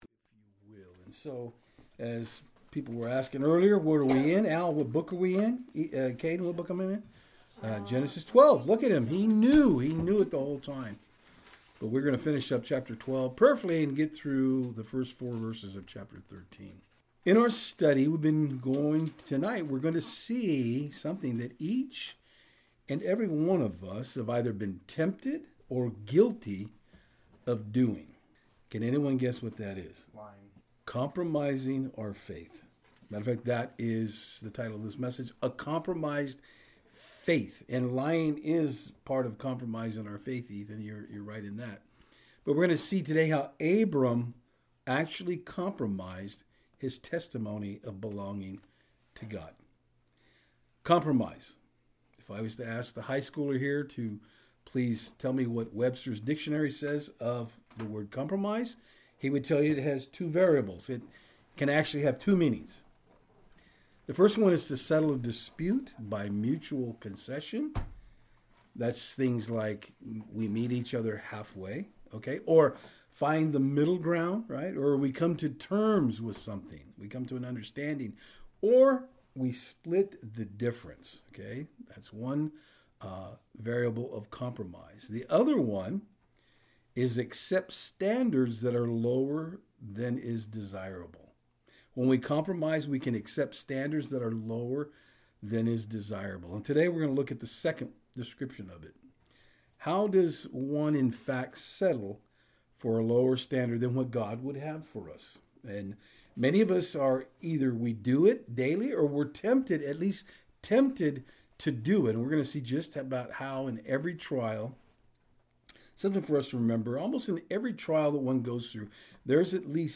Service Type: Saturdays on Fort Hill Topics: Gods Sovereignty , Obedience , Trust